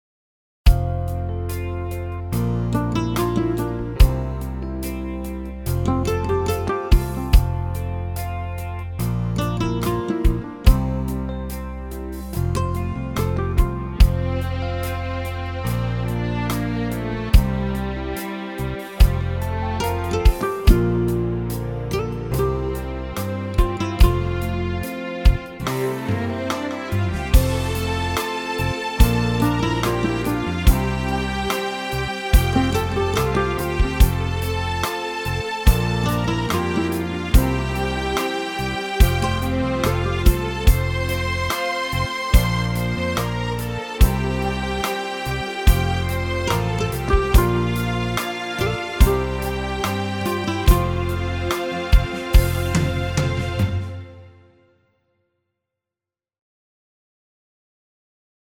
מקהלה שהוזכרה בתוכנית של מנחם טוקר של גמר מצעד מקהלות הילדים...